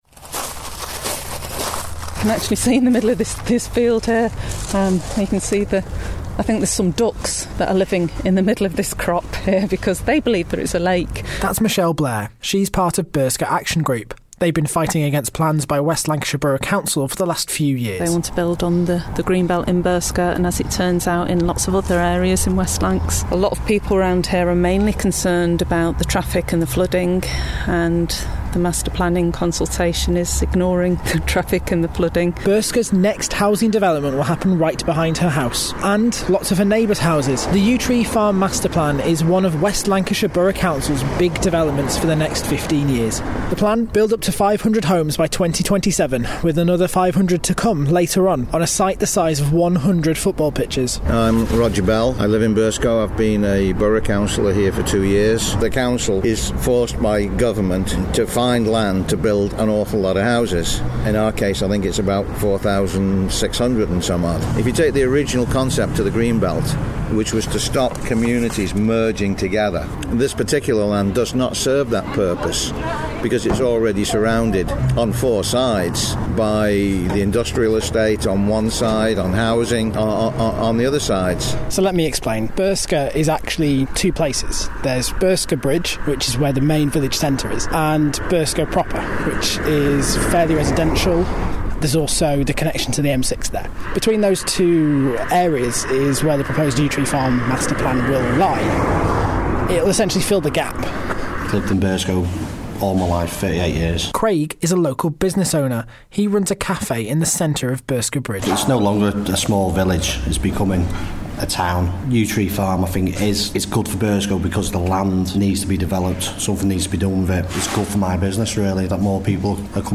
Newsreader and Reporter Demo - Summer 2015